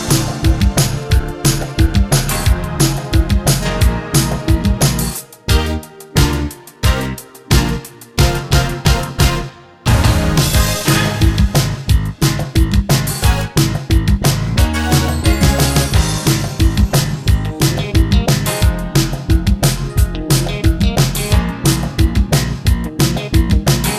Two Semitones Down Soundtracks 3:15 Buy £1.50